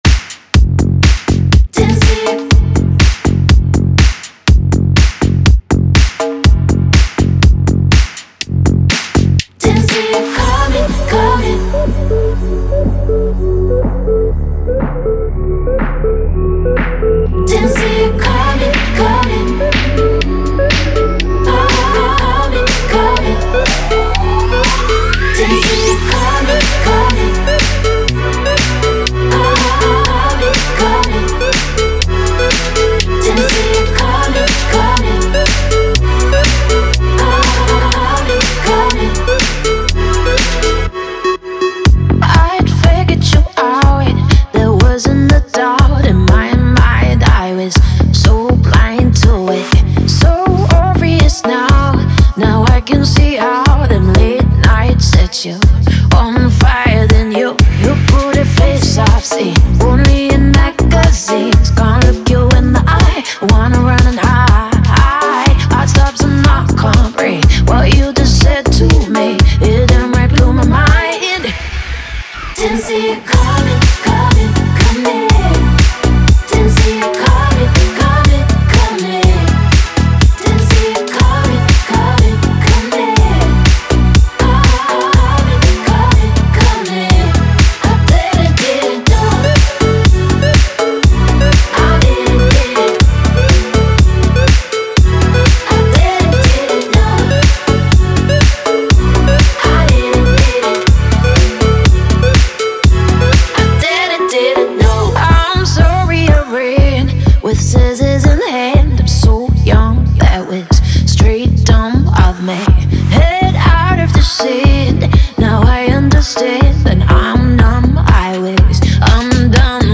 бодрые треки